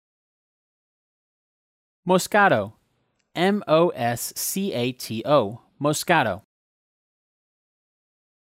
Editor’s Note: This post contains user-submitted pronunciations.
muhs-kat-tow